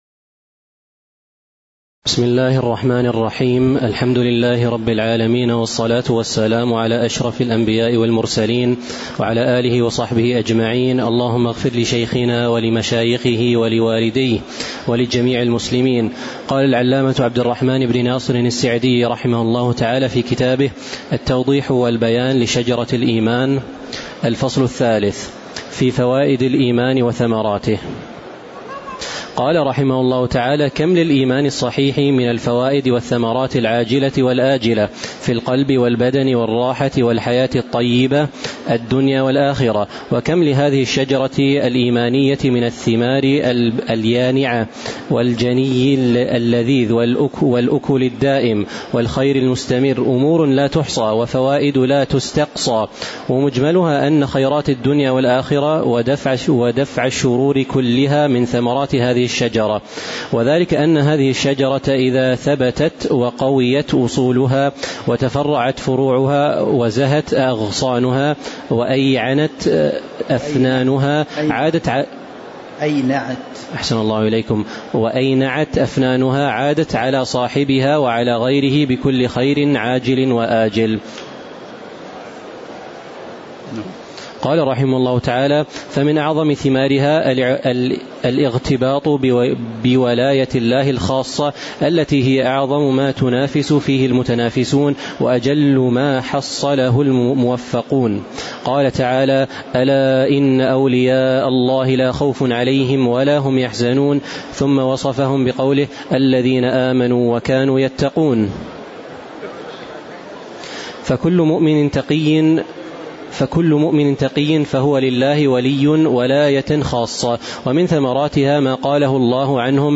تاريخ النشر ٢٩ ربيع الثاني ١٤٤٥ هـ المكان: المسجد النبوي الشيخ